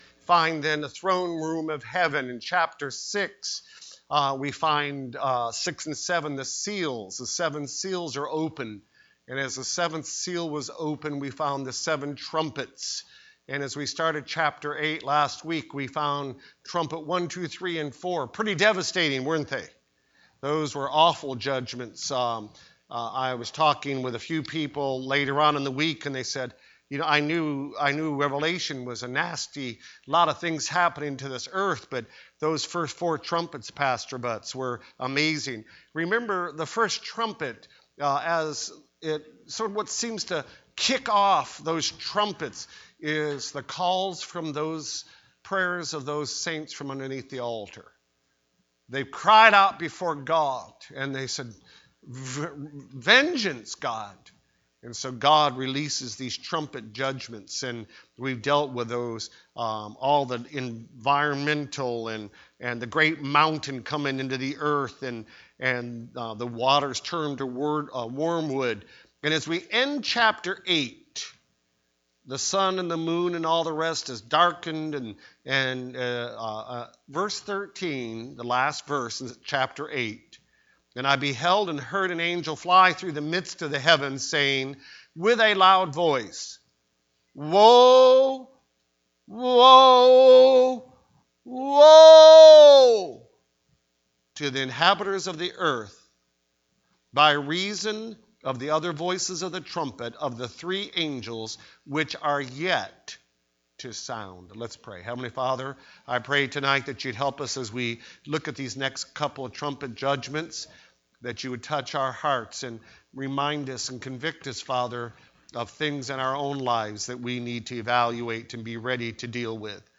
The Revelation Service Type: Sunday Evening Preacher